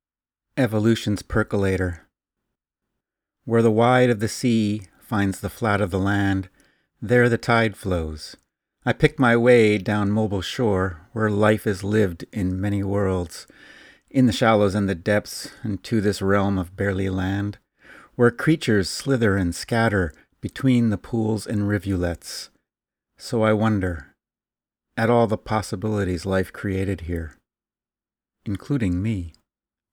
evolutions-percolator-a-poem-about-the-pivotal-role-of-the-tidal-zone-in-the-evolution-of-life-1.mp3